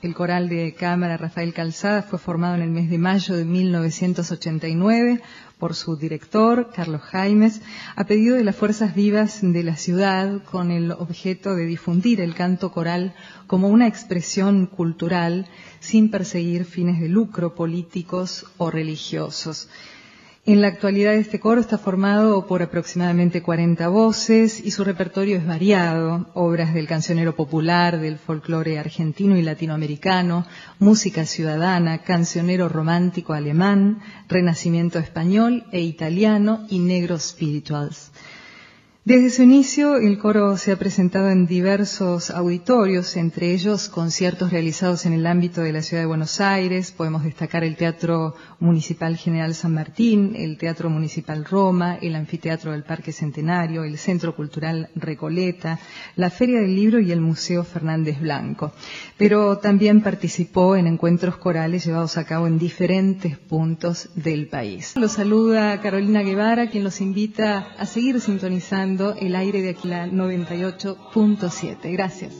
integrado por hombres y mujeres de nuestra ciudad